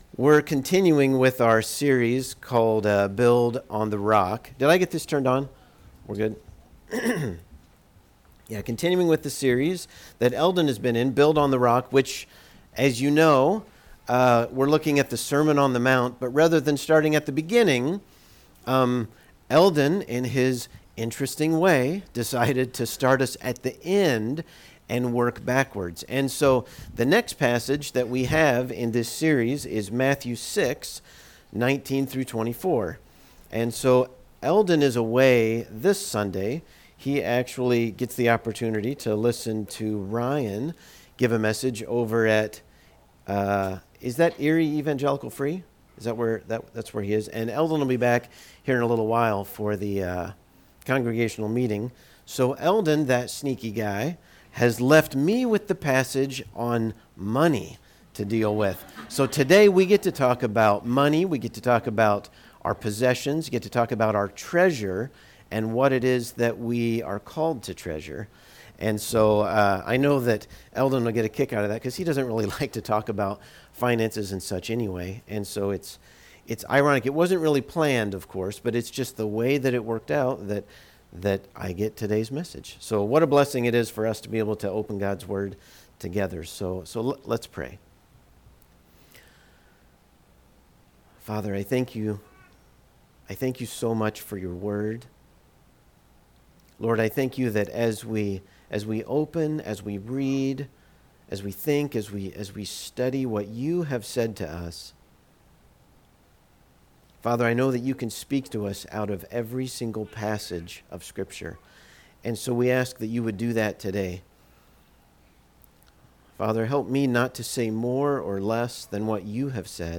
Service Type: Sunday Morning What should we think about money?